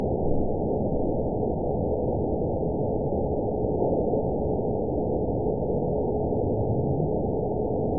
event 920074 date 02/21/24 time 20:27:00 GMT (1 year, 3 months ago) score 8.95 location TSS-AB06 detected by nrw target species NRW annotations +NRW Spectrogram: Frequency (kHz) vs. Time (s) audio not available .wav